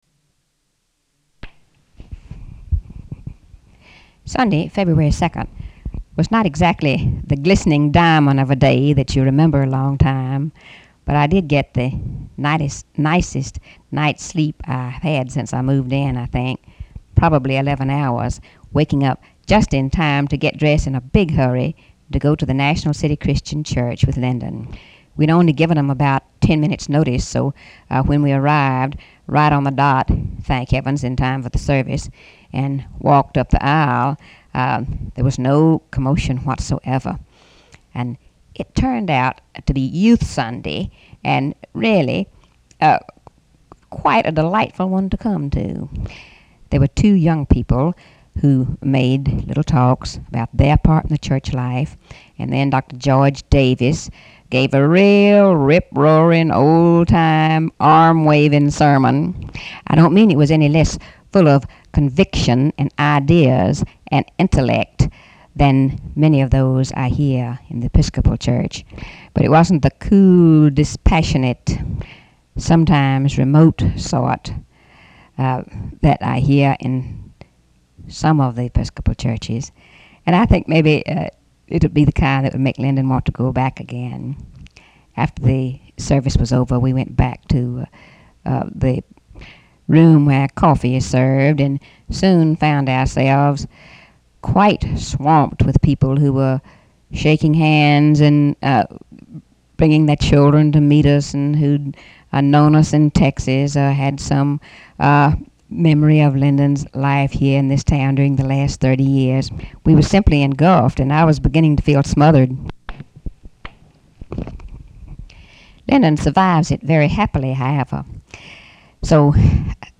Audio diary and annotated transcript, Lady Bird Johnson, 2/2/1964 (Sunday) | Discover LBJ